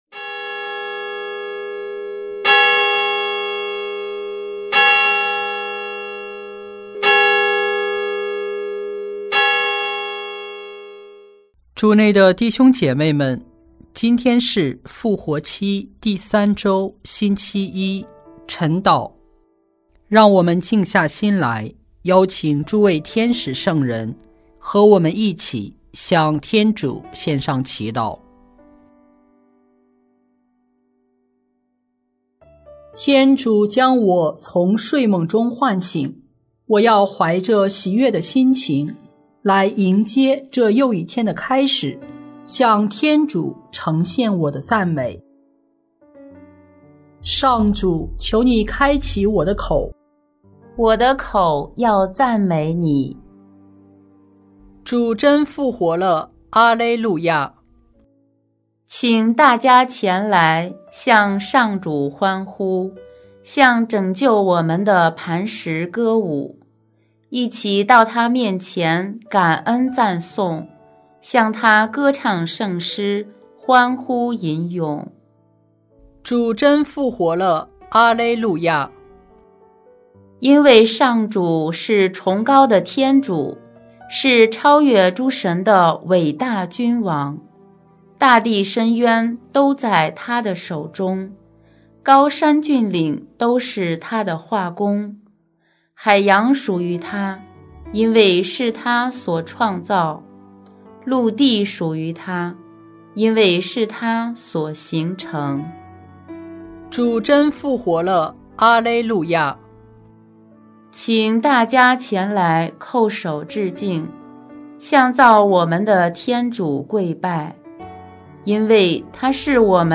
4月20日复活期第三周星期一晨祷